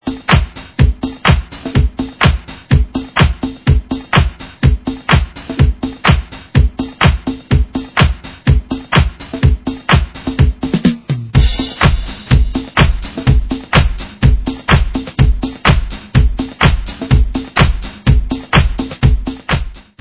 Vocal house